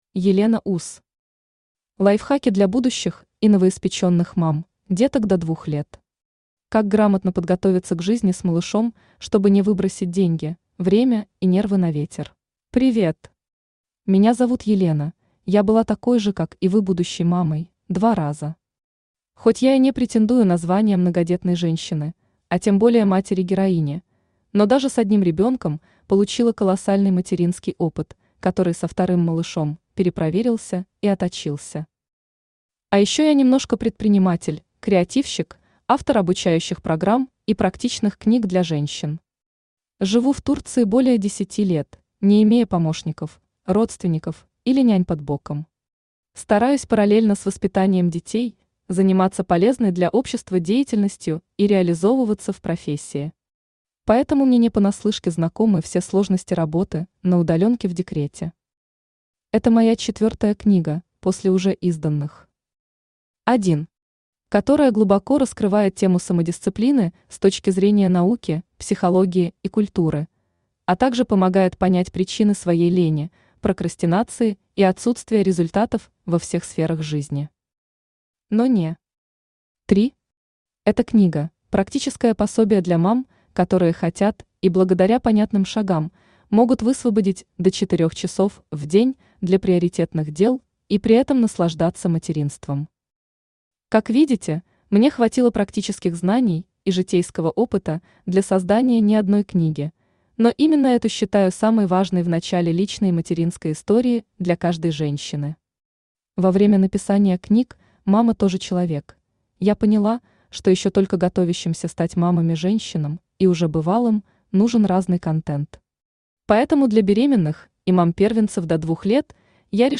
Аудиокнига Лайфхаки для будущих и новоиспечённых мам (деток до 2 лет).
Как грамотно подготовиться к жизни с малышом чтобы не выбросить деньги, время и нервы на ветер Автор Елена Уз Читает аудиокнигу Авточтец ЛитРес.